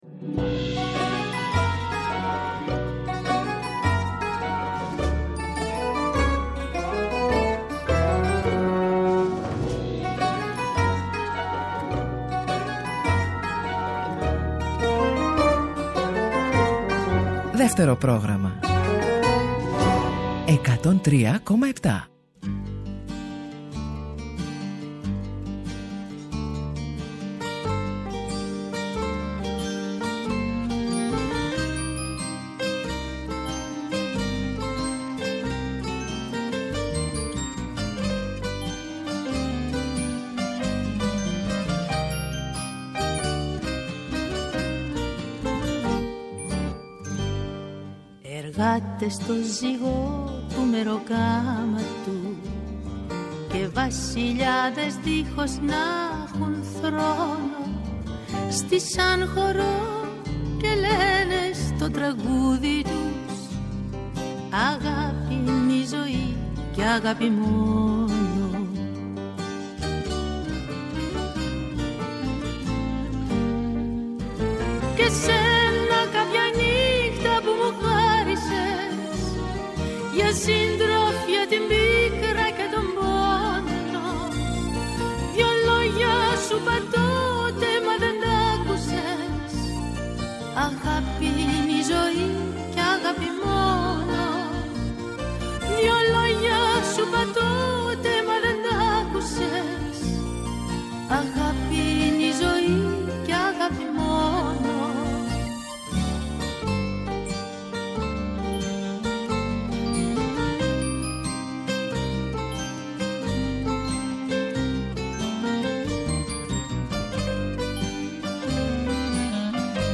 Τα τραγούδια της παρέας και πάντα το καινούργιο τραγούδι της ημέρας! Παλιά τραγούδια που κουβαλάνε μνήμες αλλά και νέα που πρόκειται να μας συντροφεύσουν.